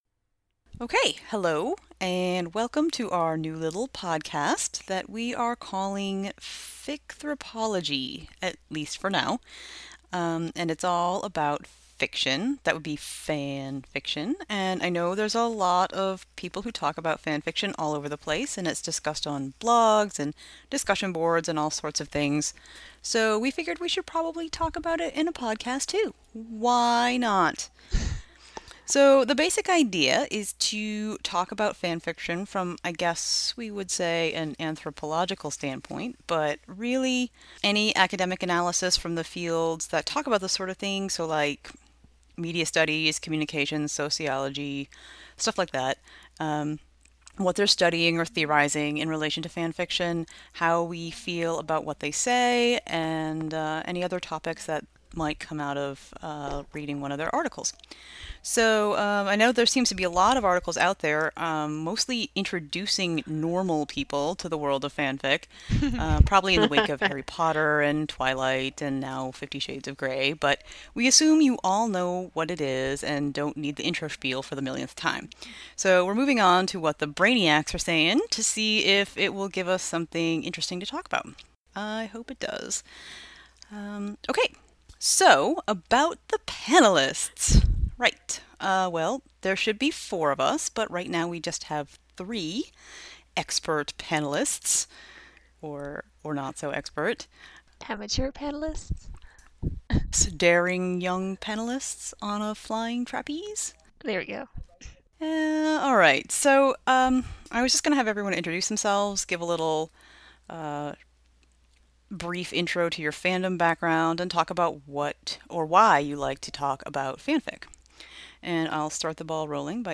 The podcast Ficthropology is designed as a discussion of the wonderful world of fan fiction from a critical standpoint. Modeled after an informal academic discussion group, we generally tackle one article, book, or theme per episode, from any field from anthropology, media studies, communications, women's studies - whoever has an interesting take of fanfic, its writers, readers, community, genres, themes, trends, or tropes.